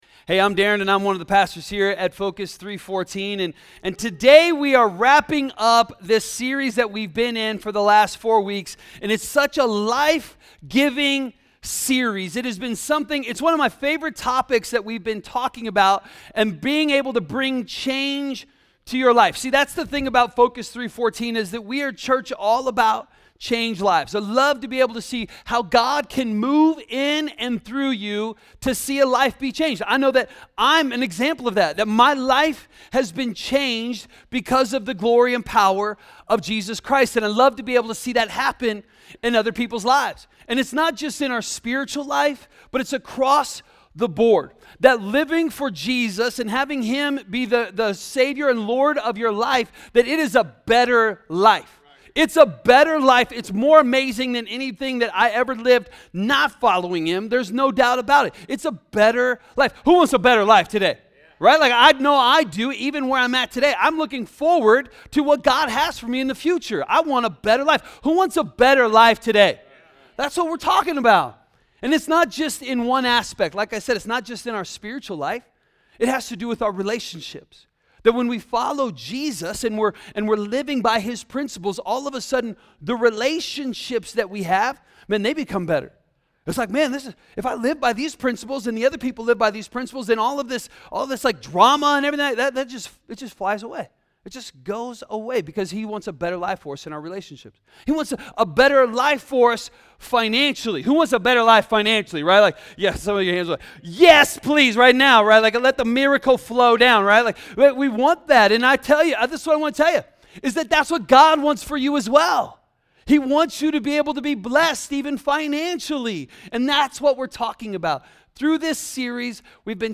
A message from the series “Making Change.”